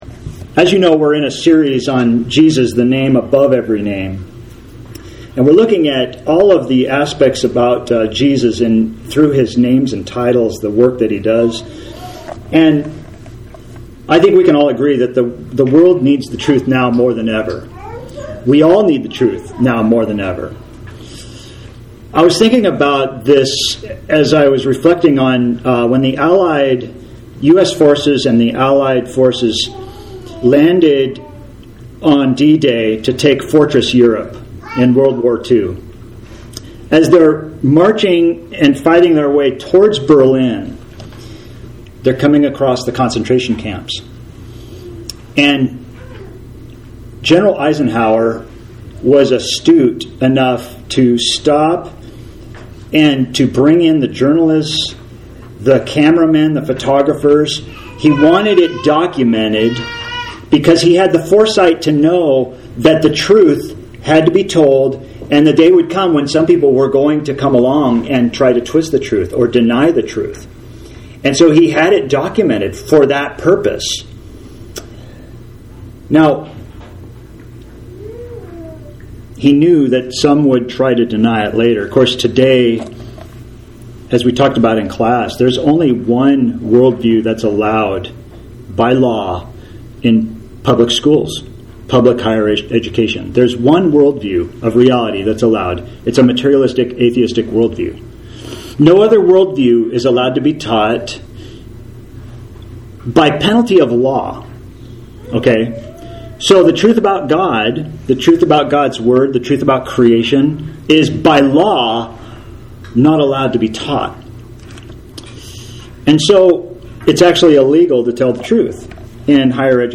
(Acts 4:12) AUDIO | TEXT PDF Jesus our Savior Author jstchurchofchrist Posted on November 6, 2022 November 6, 2022 Categories Sermons Tags Jesus , Names of Jesus